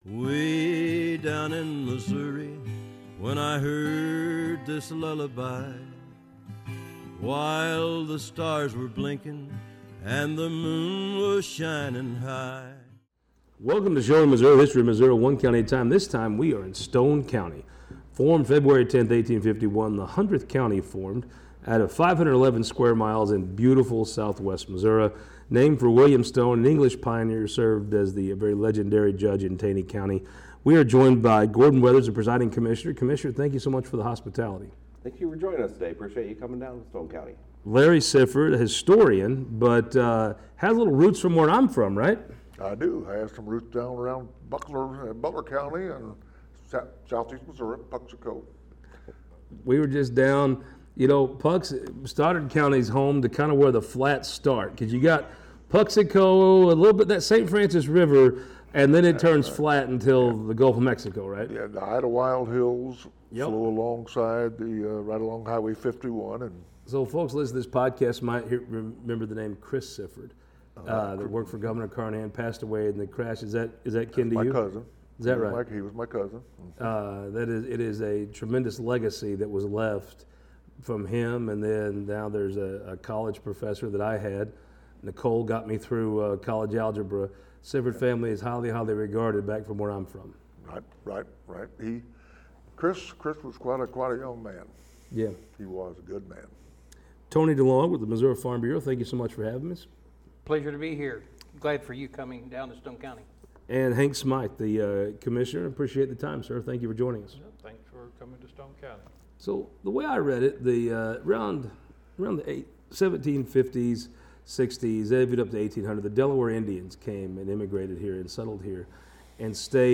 to talk with local guests about the people and history that shaped this scenic region.